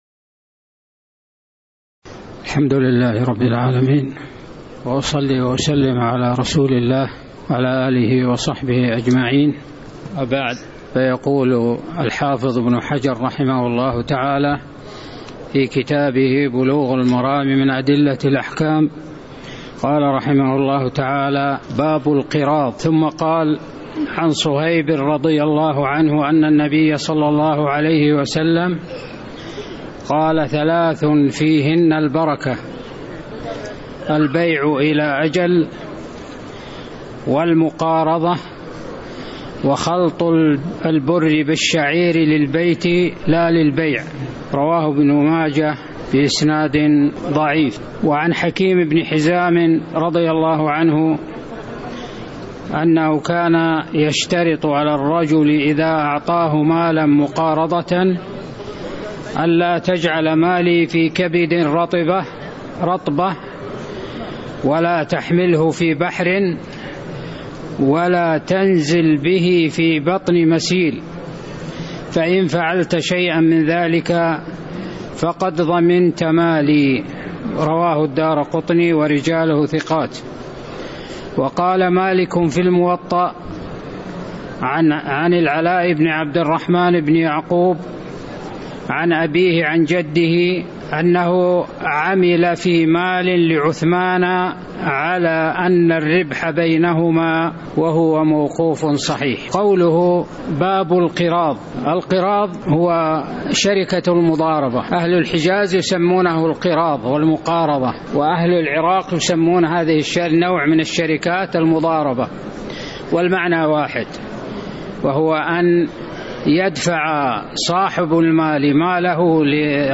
تاريخ النشر ٥ رجب ١٤٤٠ هـ المكان: المسجد النبوي الشيخ